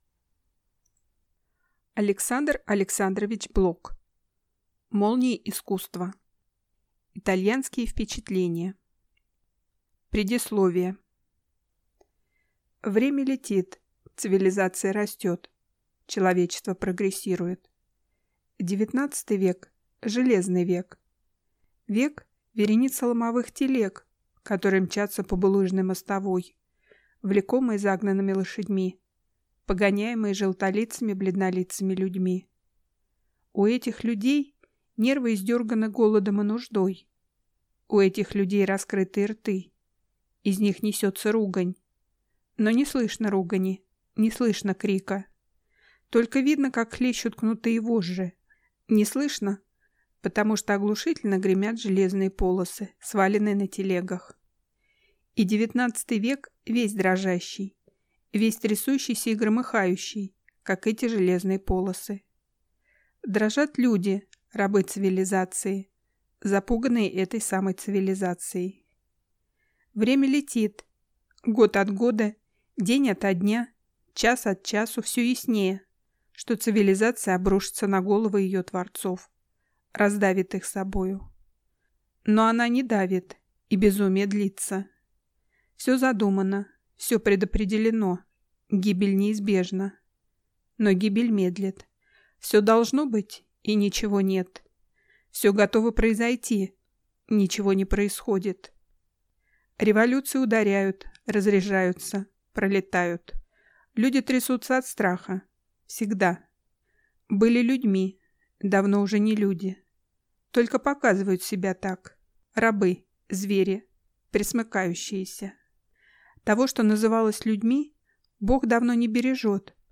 Аудиокнига Молнии искусства | Библиотека аудиокниг